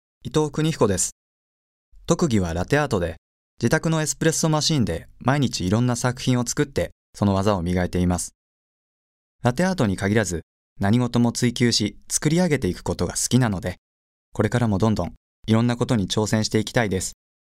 出⾝地・⽅⾔ 宮城県・東北弁
ボイスサンプル